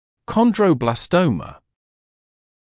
[ˌkɒndrə(u)ˌblɑːs’təumə][ˌкондро(у)ˌблаːс’тоумэ]хондробластома